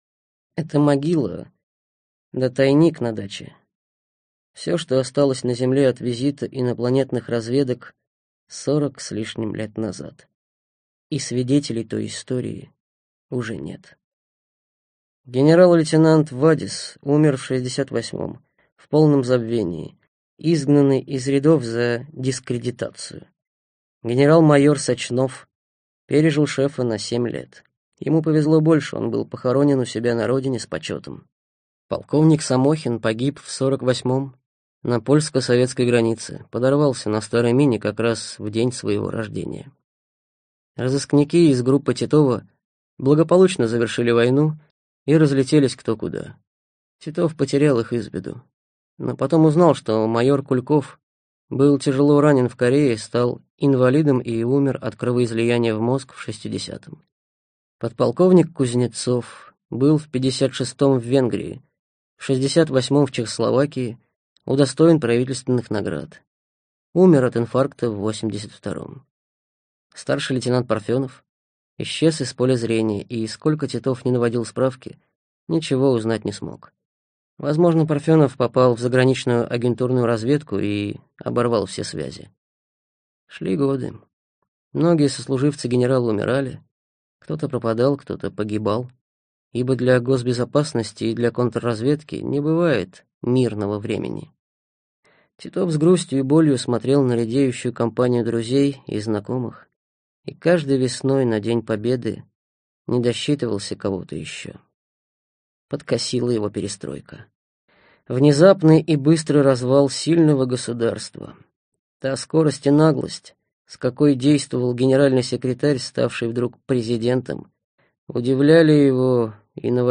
Аудиокнига Внешняя угроза: Второй шанс | Библиотека аудиокниг